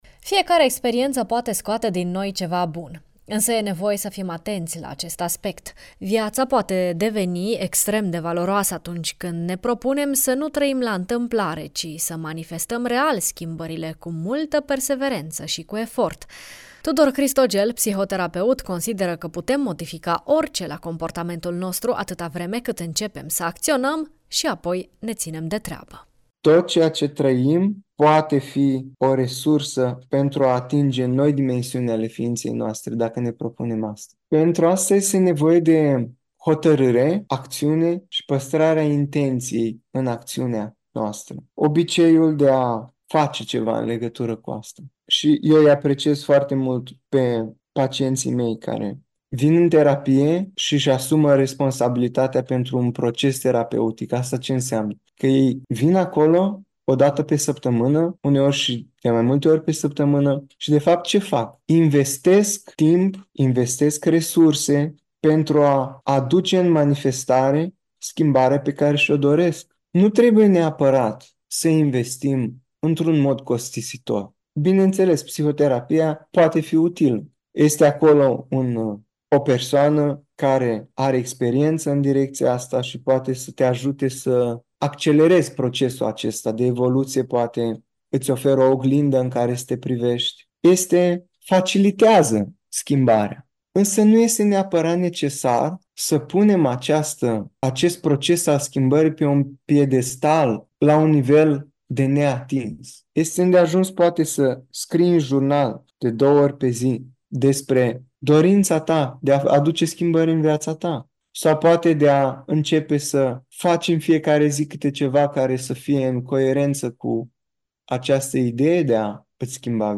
psihoterapeut